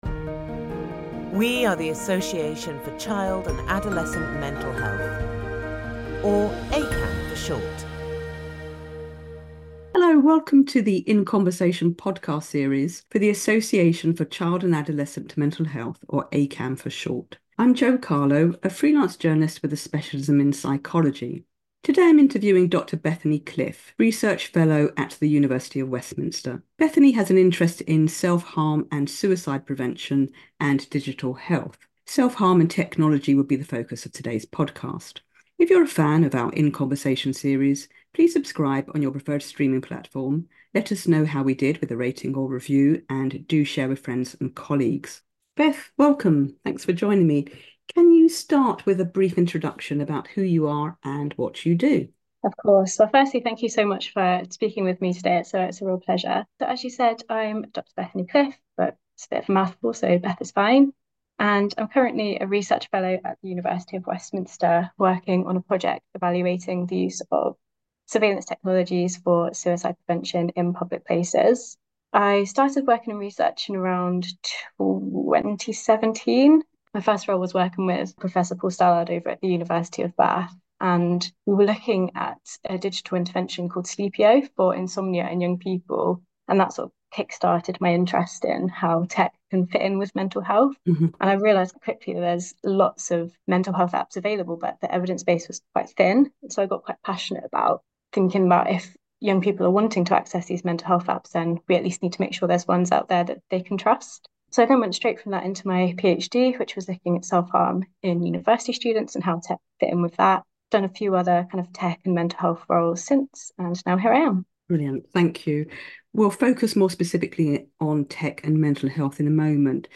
In Conversation podcast